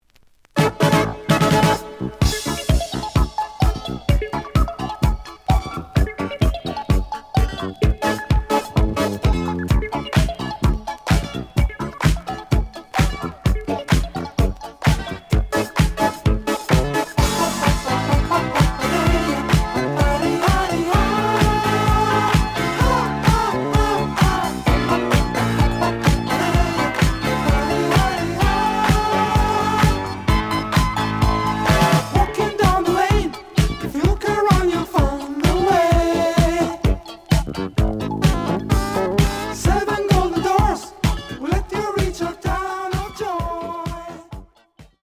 The audio sample is recorded from the actual item.
●Genre: Disco
Edge warp. But doesn't affect playing. Plays good.)